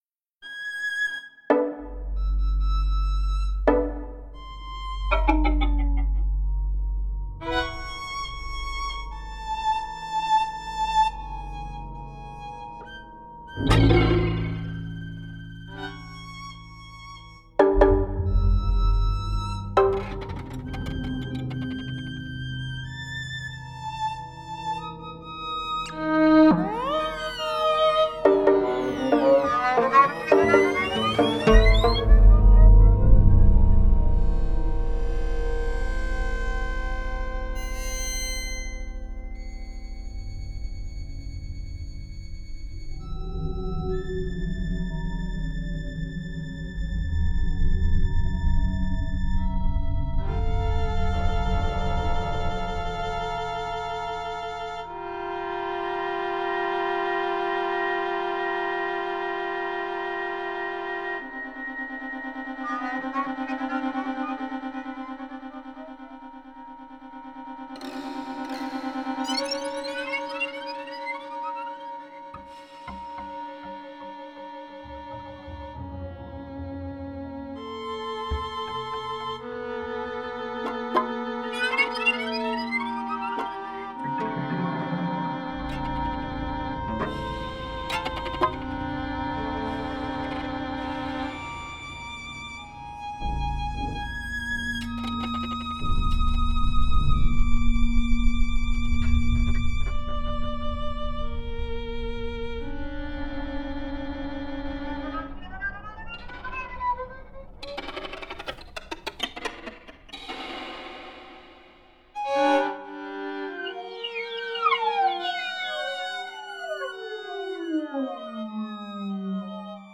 Ignore the Smoke - Violin and Fixed Media
For this piece, I have mapped pitches onto the violin in a similar manner to Castelnuovo-Tedesco and additionally have used morse code to map rhythms onto these pitch materials.
Realized in the University of Illinois Experimental Music Studios.